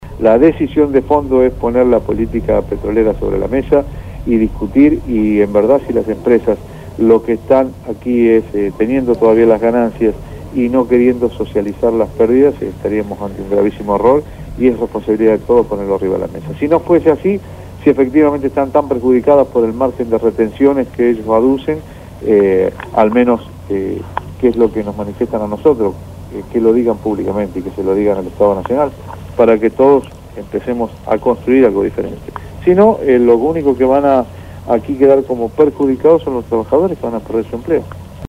entrevistaron a Alberto Roberti, Secretario General de de la Federación Argentina Sindical de Petróleo y Gas Privados (FASPyGP).